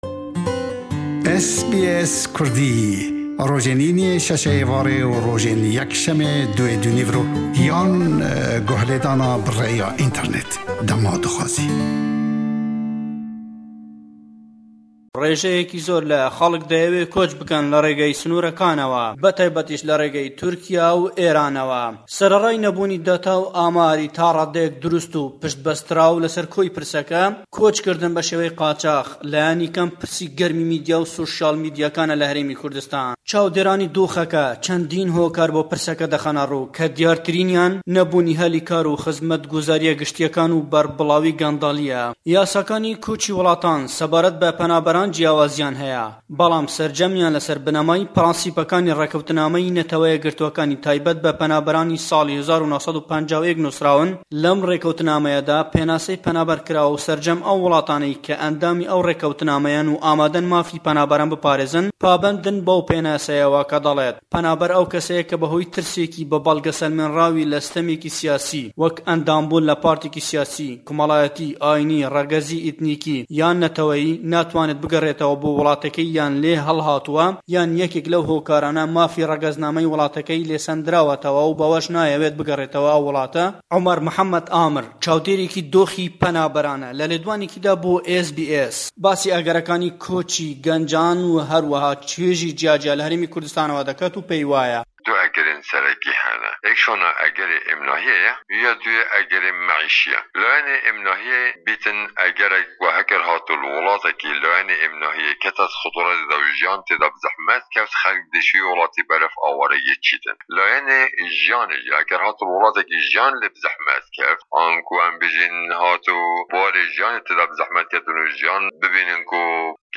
di raporta xwe ya ji Hewlêrê de li ser rewşê radighîne.